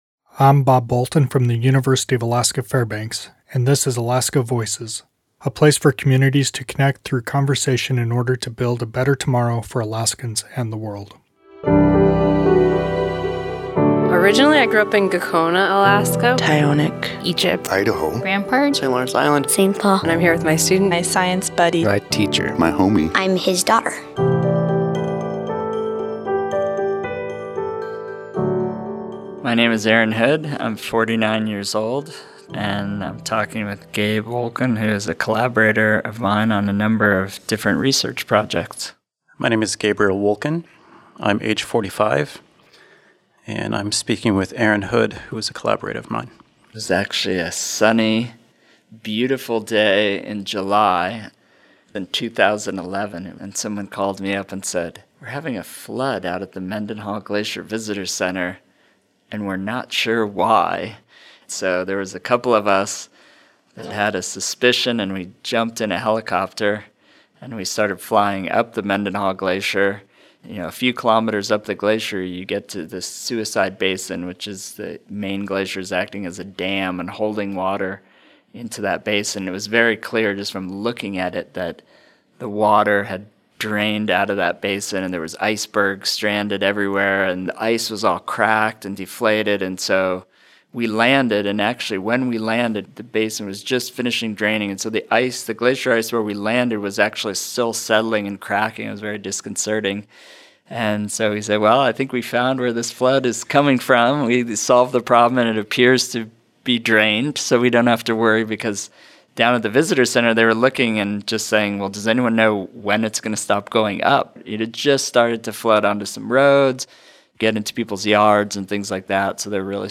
2018 at the University of Alaska Fairbanks in Fairbanks, Alaska.
Music: “Arctic Evening” by Marcel du Preez